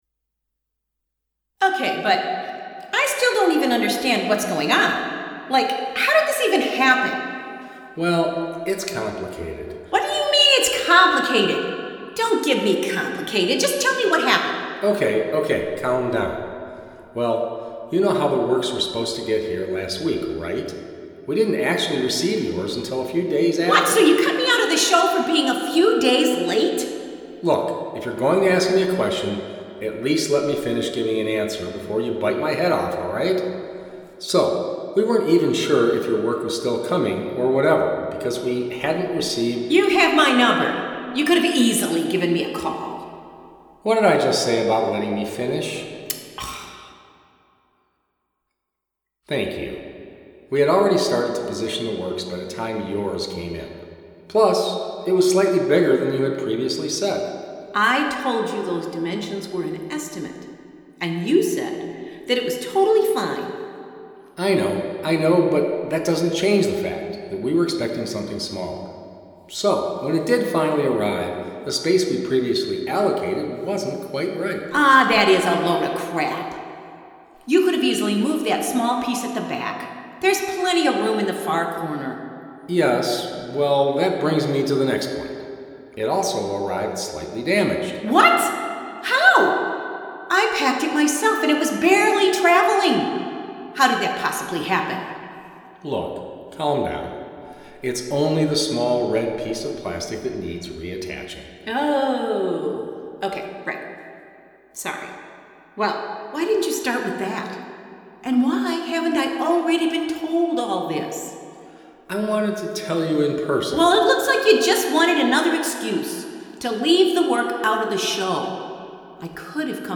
9 - 10 November 2019 Interviews with artists listen here